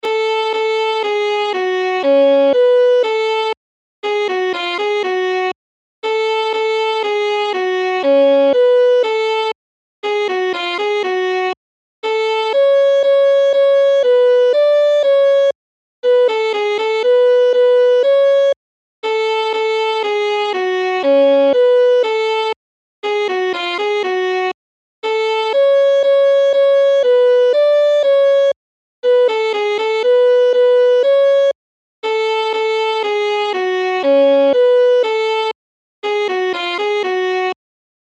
Aranžmá Noty na housle
Hudební žánr Lidovky